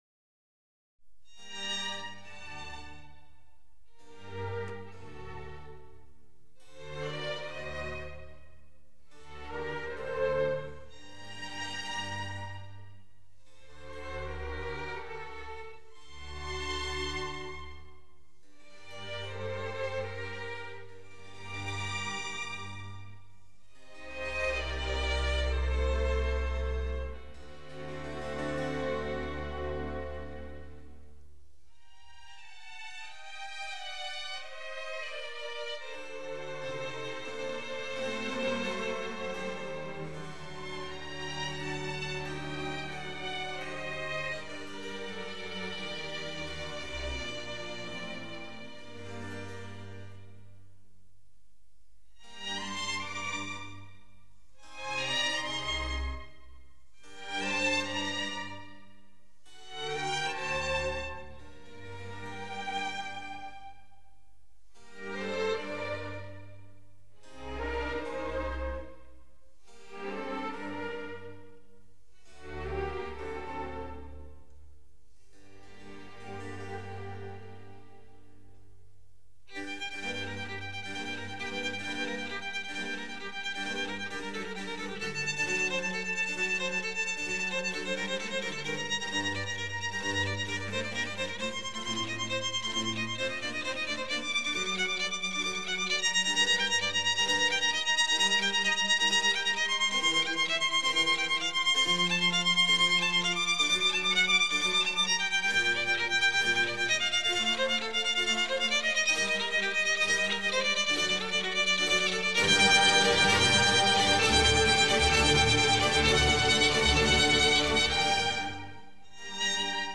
Concerto No. 2 in G minor
Allegro non molto (in G minor) Adagio e piano – Presto e forte (in G minor) Presto (in G minor)